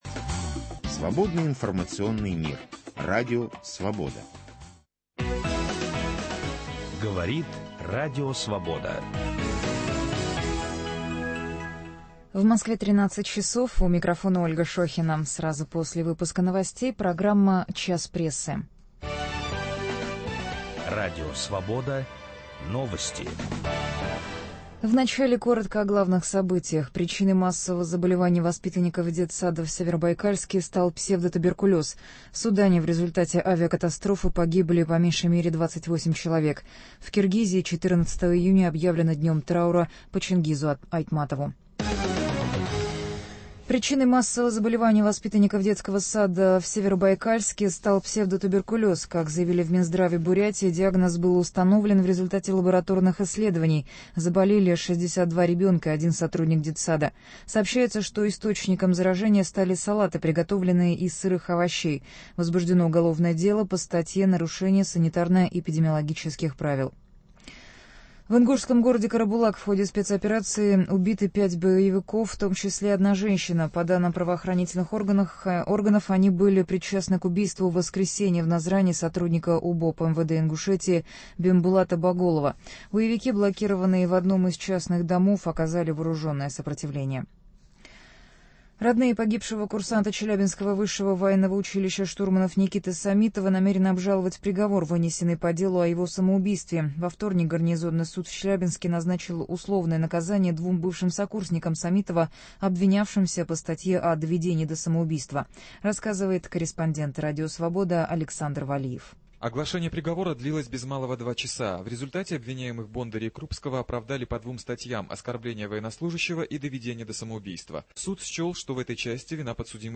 Возможна ли политическая оттепель и готовы ли к ней российские СМИ? Виталий Портников беседует с Евгением Киселевым